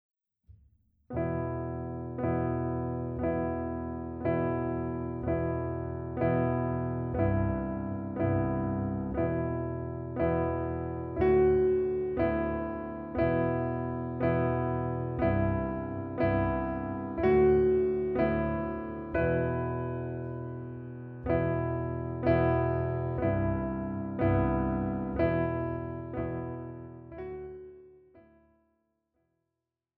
Besetzung: Oboe und Klavier